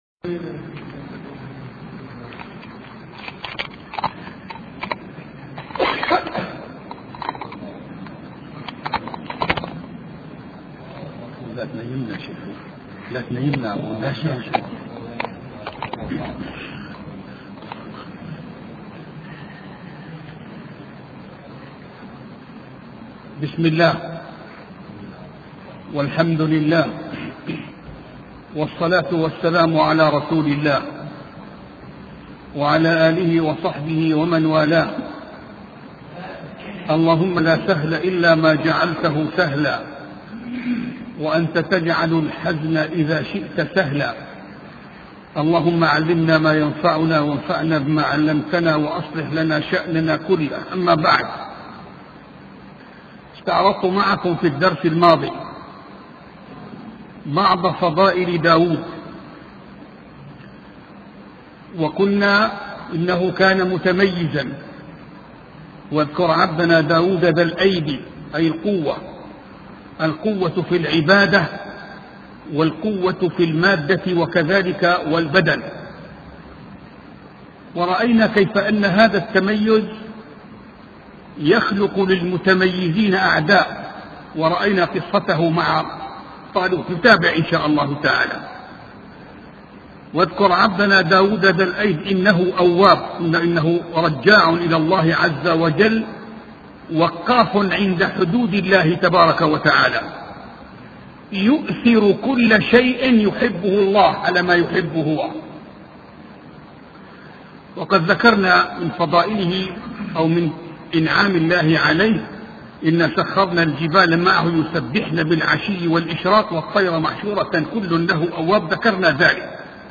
سلسلة محاضرات في قصة داود عليه السلام